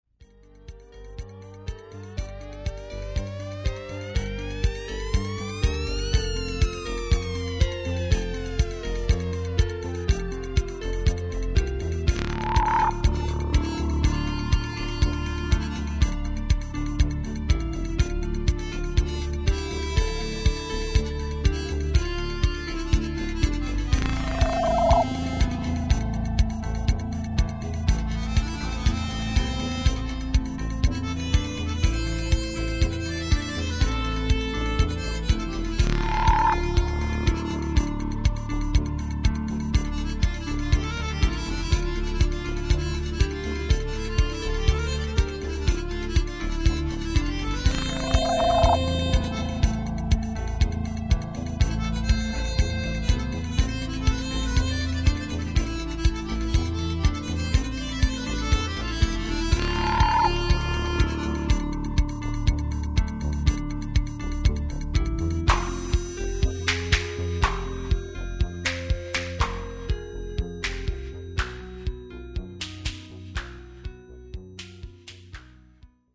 Musique électronique